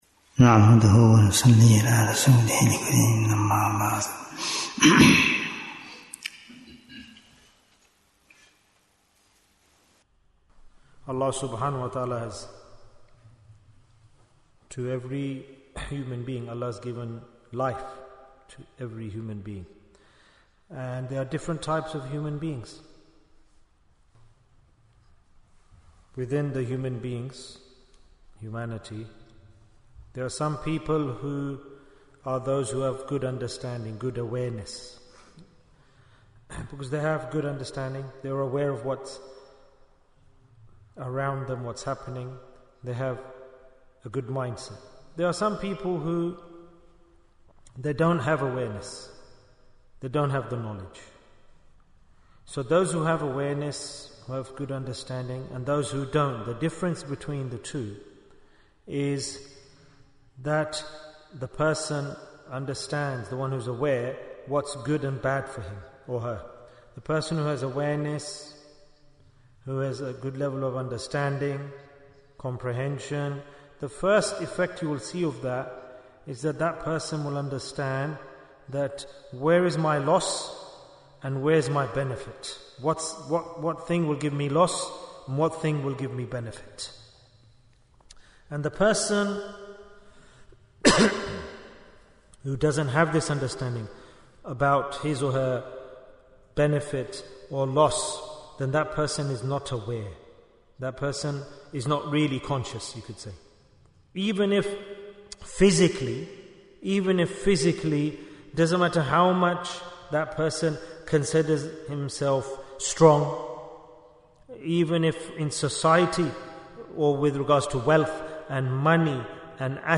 Jewels of Ramadhan 2025 - Episode 18 - The Beauty of Islam Bayan, 21 minutes15th March, 2025